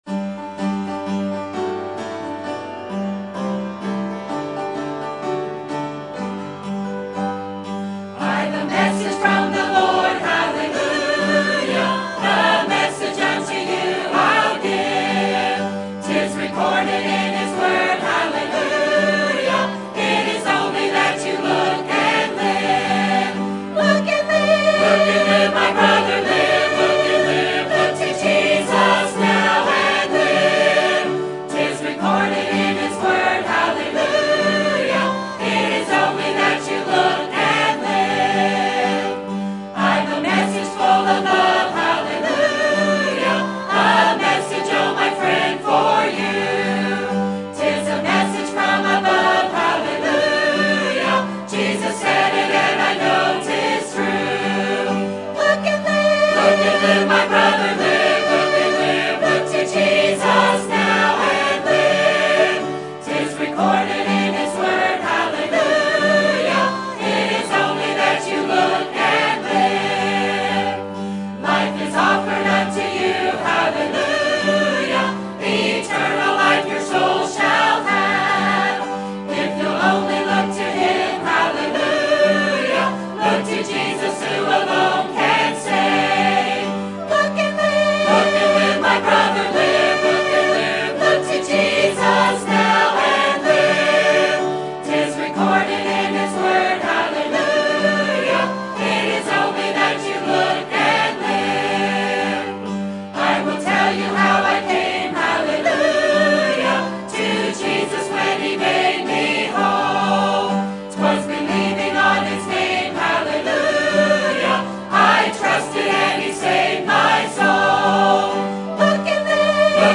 Sermon Topic: General Sermon Type: Service Sermon Audio: Sermon download: Download (31.1 MB) Sermon Tags: I Kings Repentance God Sins